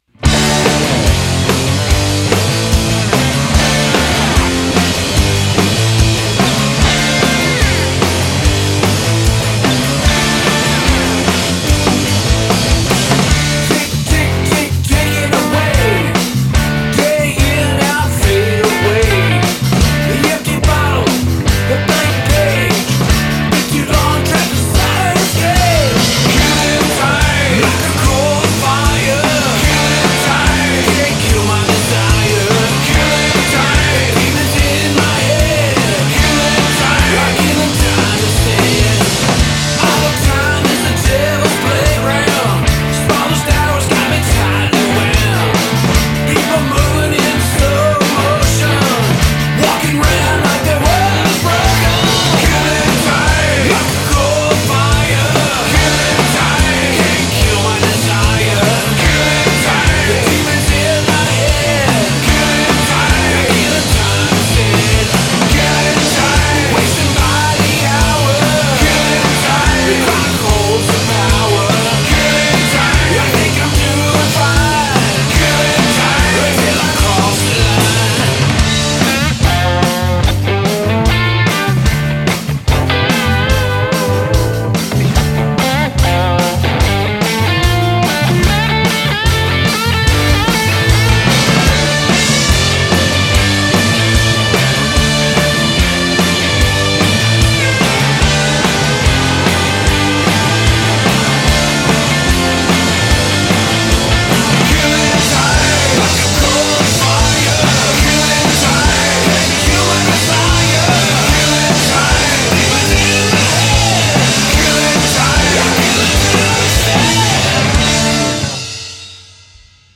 lead and background vocals; guitars, bass and drums